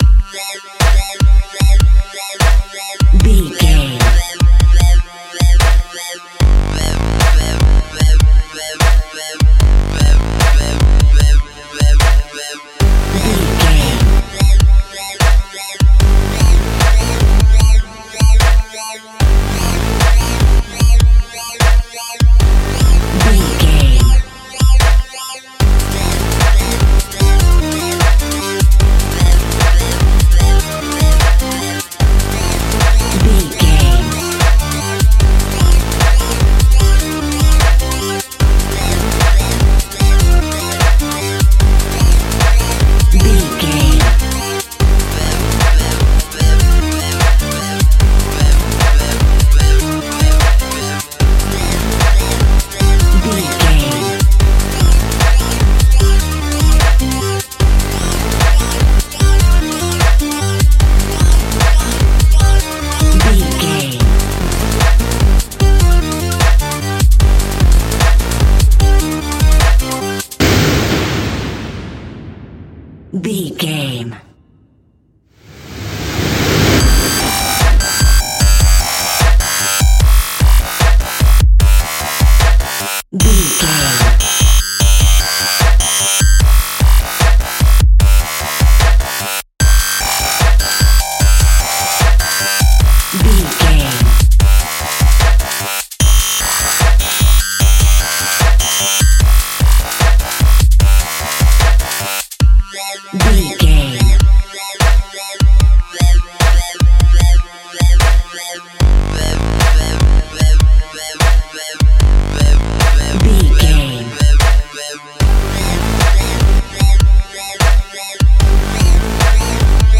Epic / Action
Fast paced
In-crescendo
Aeolian/Minor
aggressive
dark
energetic
driving
futuristic
synthesiser
drum machine
strings
breakbeat
synth leads
synth bass